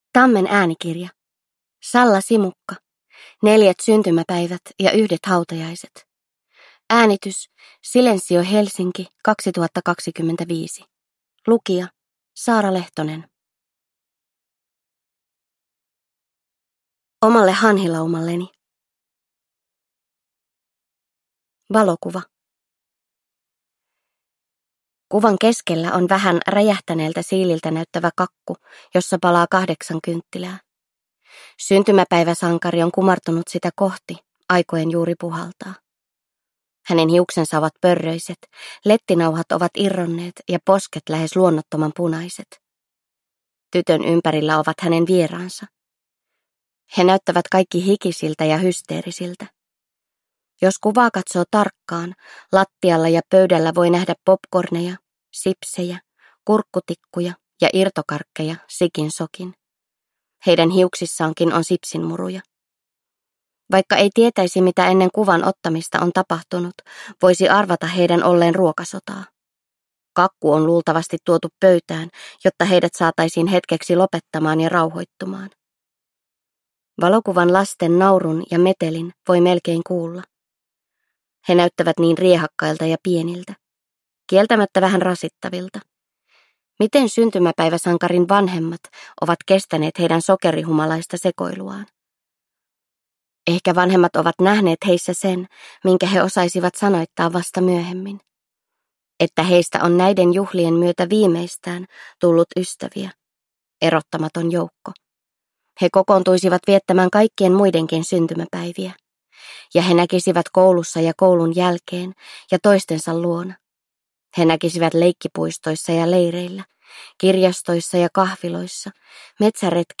Neljät syntymäpäivät ja yhdet hautajaiset – Ljudbok
• Ljudbok